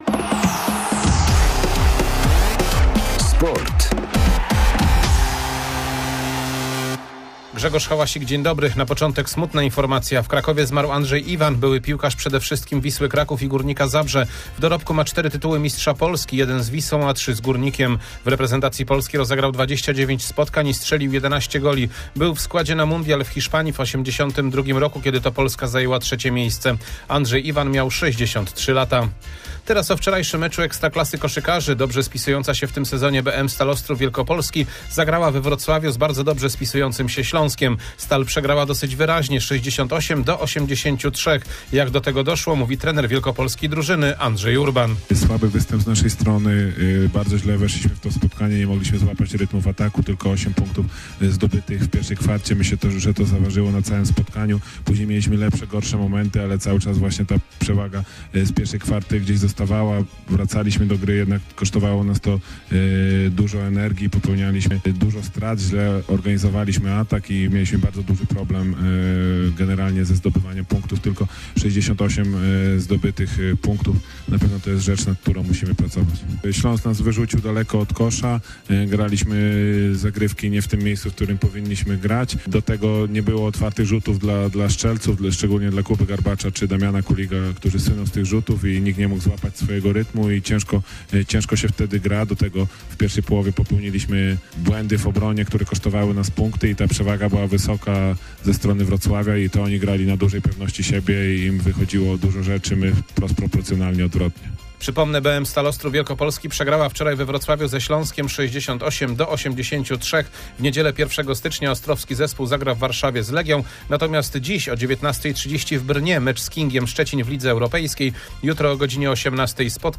27.12.2022 SERWIS SPORTOWY GODZ. 19:05